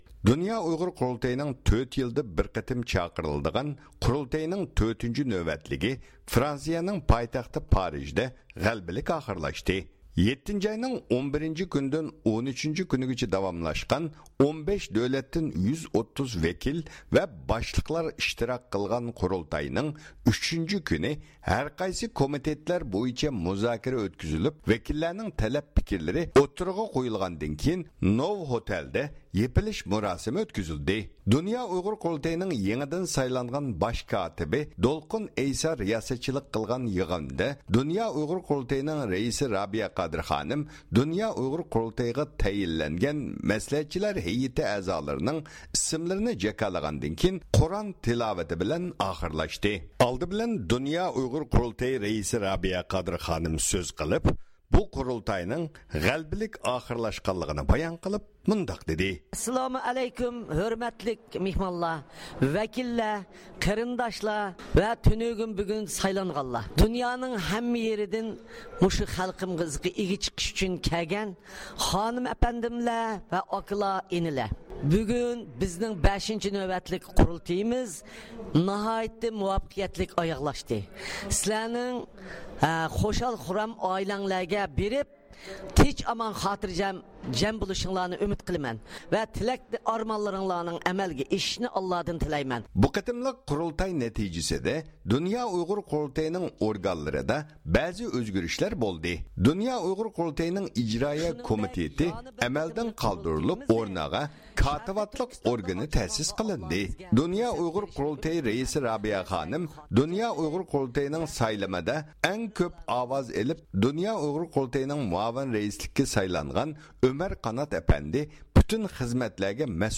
7-ئاينىڭ 11-كۈنىدىن 13-كۈنىگىچە داۋاملاشقان 15 دۆلەتتىن 130 ۋەكىل ۋە باشقىلار ئىشتىراك قىلغان قۇرۇلتاينىڭ 3-كۈنى ھەرقايسى كومىتېتلار بويىچە مۇزاكىرە ئۆتكۈزۈلۈپ، ۋەكىللەرنىڭ تەلەپ-پىكىرلىرى ئوتتۇرىغا قويۇلغاندىن كېيىن، نوۋاتېل مېھمانخانىسىدا يېپىلىش مۇراسىمى ئۆتكۈزۈلدى.